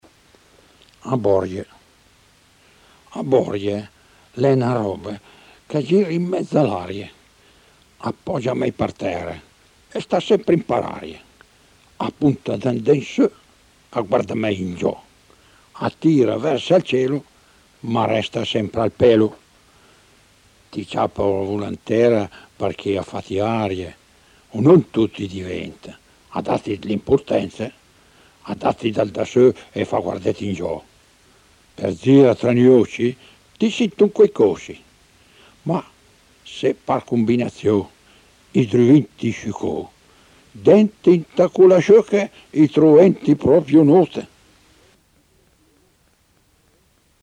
poesia composta e recitata